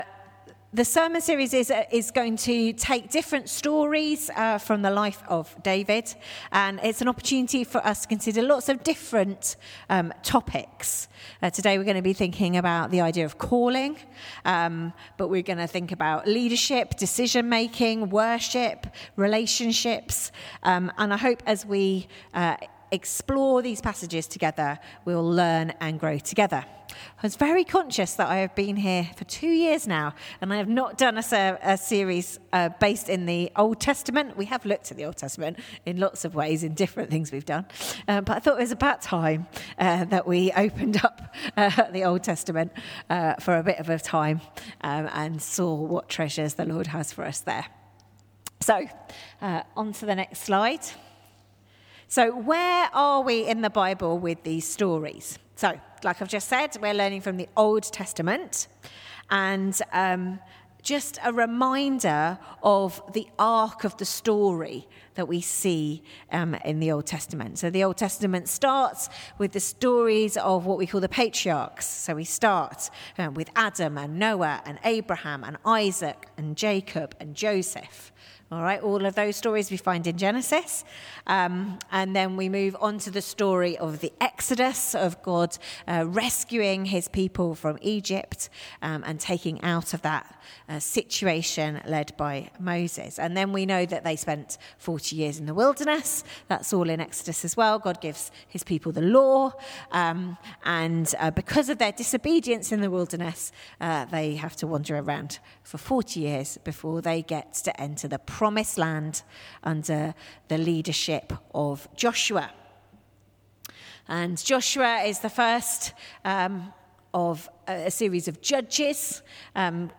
Sermon 5th May 2025